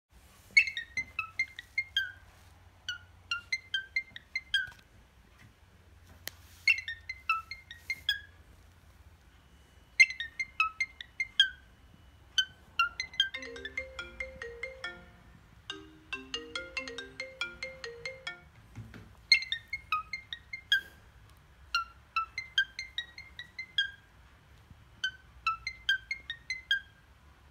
دانلود آهنگ عروس هلندی 5 از افکت صوتی انسان و موجودات زنده
جلوه های صوتی
دانلود صدای عروس هلندی 5 از ساعد نیوز با لینک مستقیم و کیفیت بالا